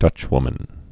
(dŭchwmən)